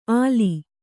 ♪ āli